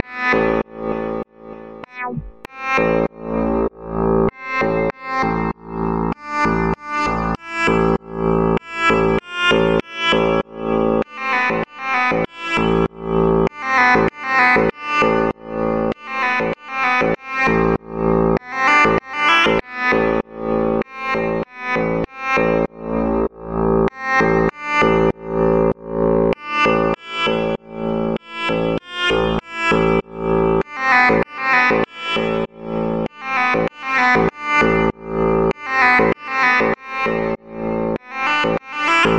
描述：一段短小的放克音乐，由土耳其单簧管提供土耳其式的扭曲。
标签： 循环 吉他 土耳其单簧管
声道立体声